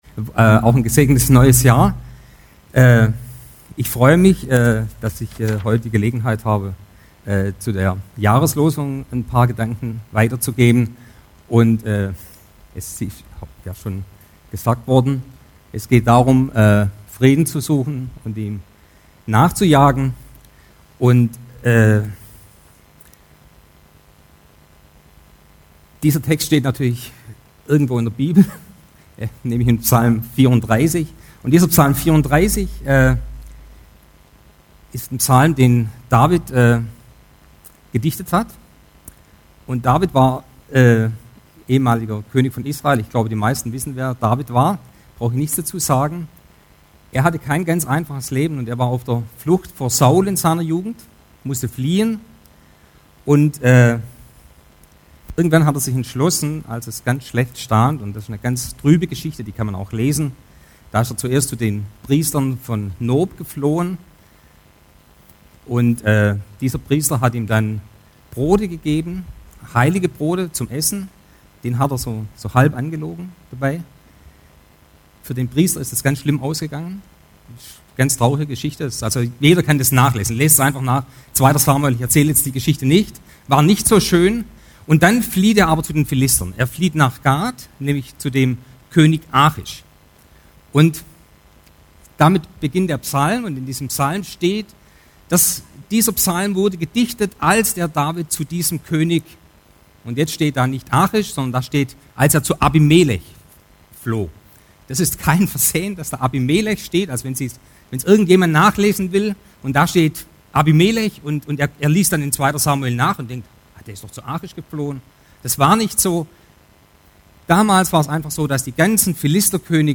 Series: Einzelpredigten